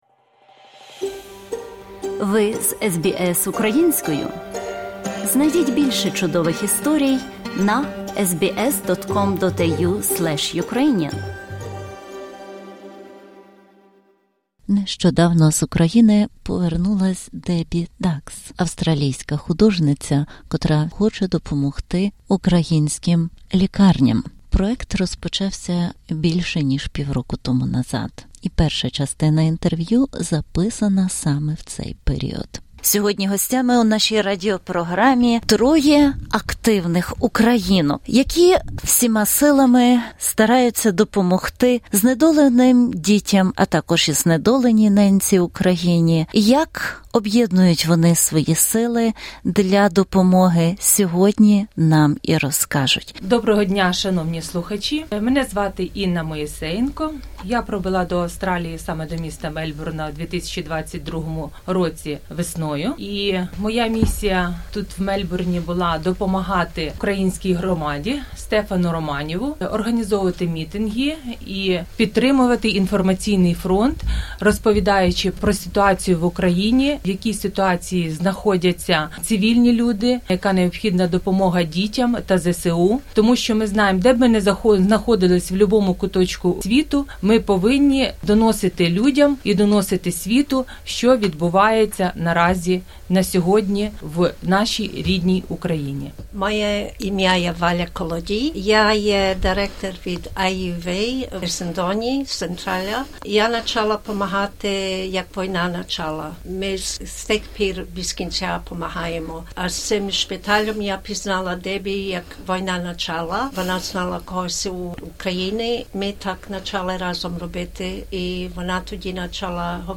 інтерв'ю